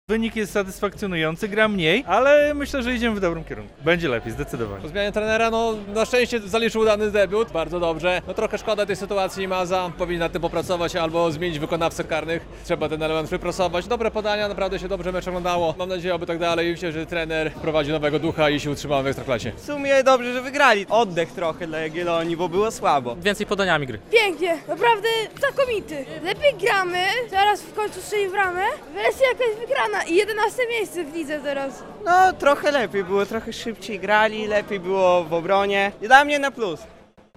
Kibice Jagiellonii nie ukrywają zadowolenia z wyniku - relacja